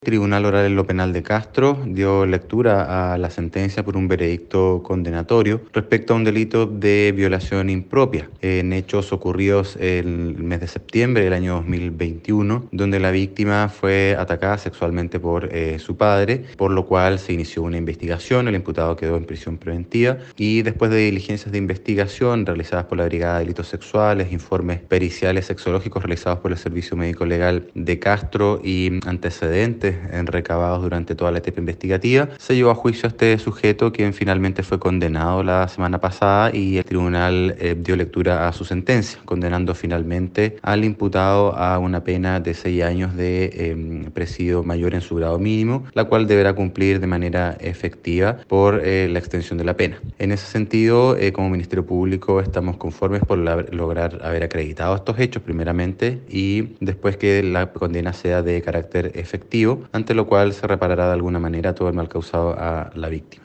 Respecto a esta sentencia se refirió el Fiscal de Castro, Luis Barría: